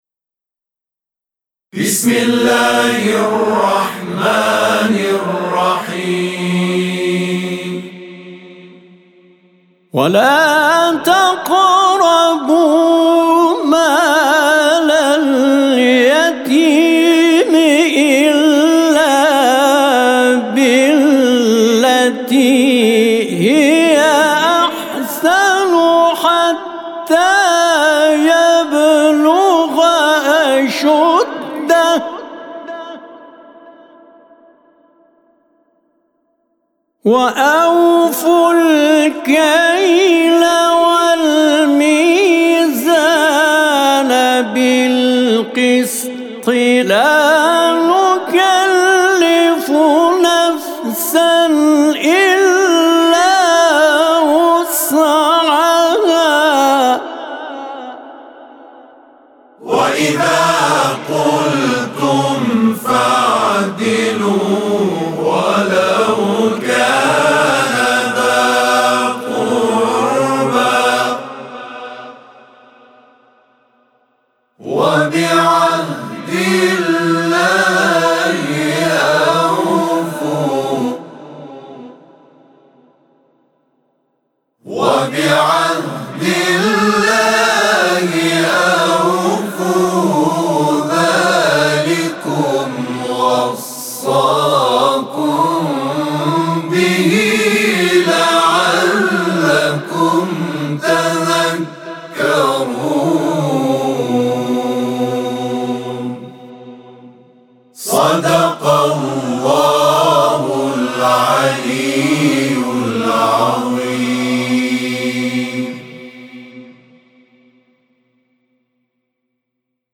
صوت همخوانی آیه 152سوره انعام از سوی گروه تواشیح «محمد رسول‌الله(ص)